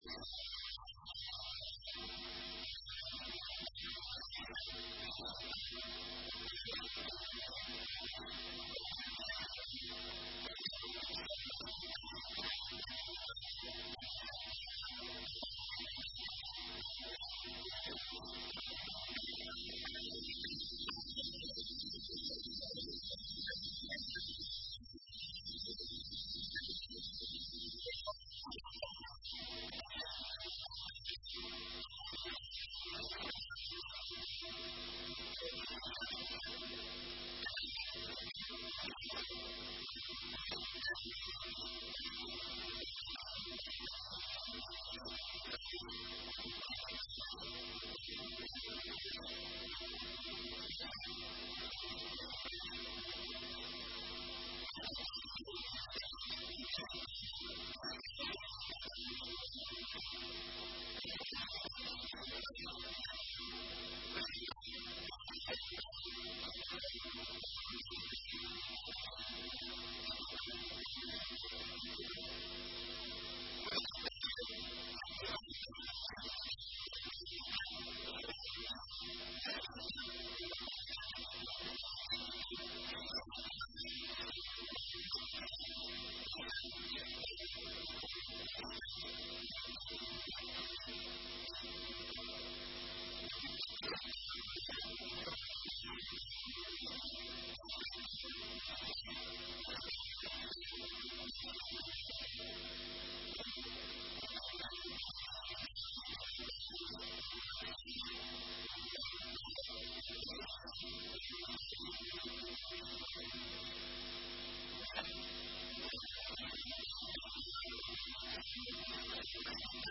Intervenção do Secretário Regional da Agricultura e Ambiente
Texto integral da intervenção do Secretário Regional da Agricultura e Ambiente, Luís Neto Viveiros, proferida hoje na Assembleia Legislativ...